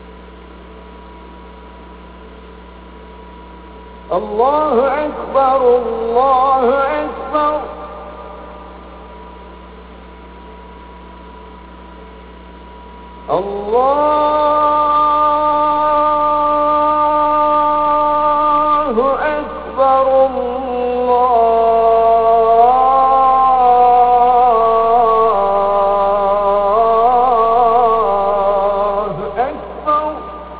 Ezan Click to listen to Ezan (call to prayer) from Nicosia's Arabahmet Mosque
ezan.au